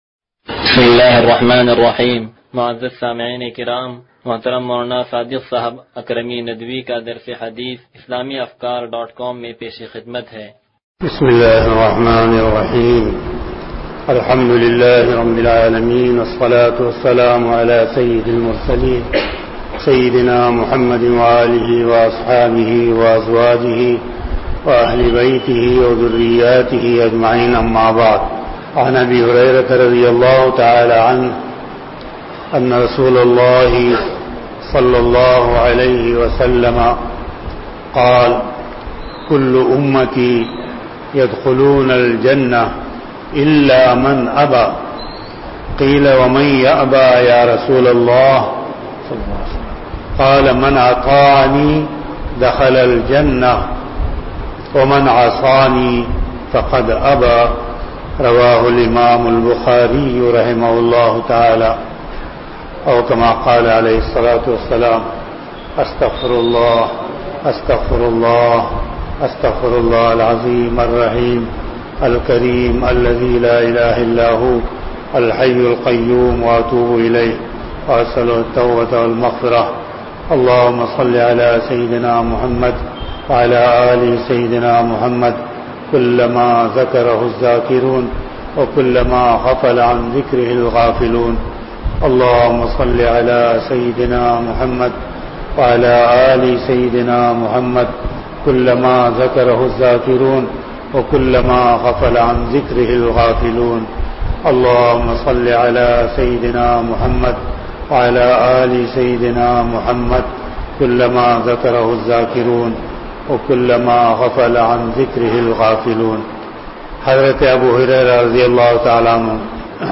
درس حدیث نمبر 0166